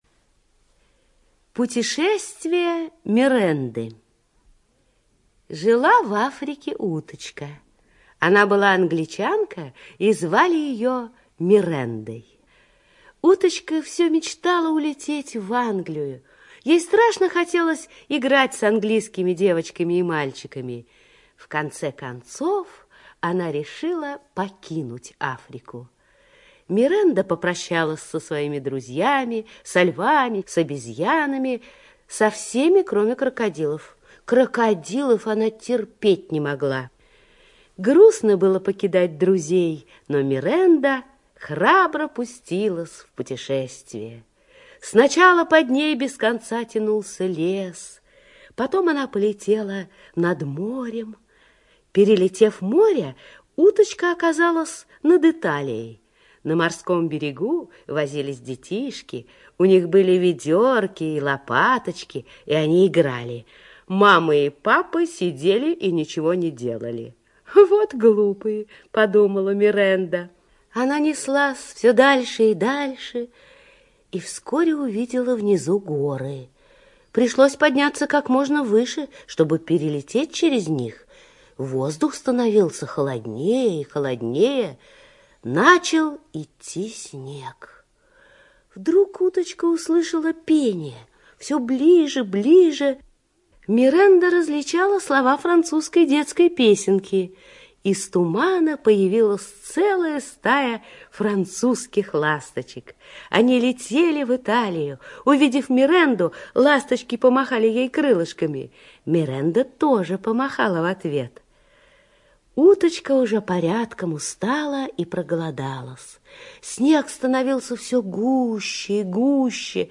Путешествие Мирэнды - аудиосказка Биссета. Жила-была в Африке утка.